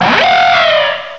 cry_not_archeops.aif